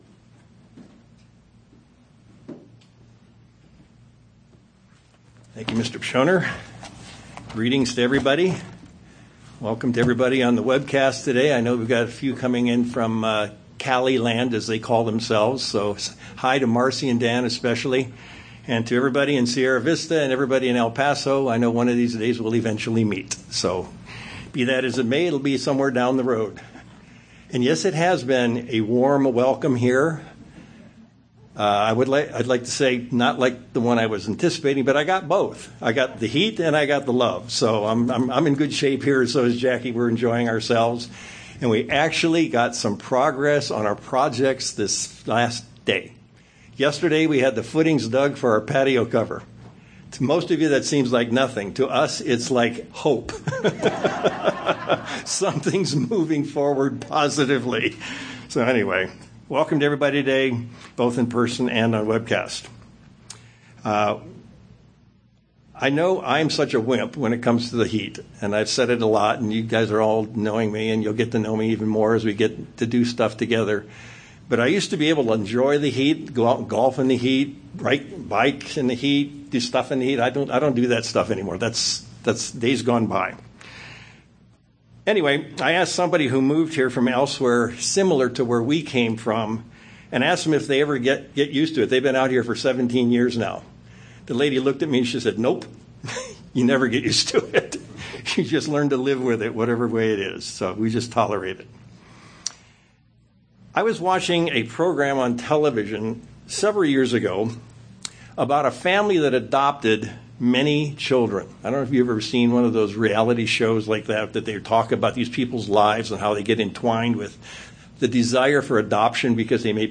We'll do that in this sermon and find out this is a really powerful formula for our Christian lives.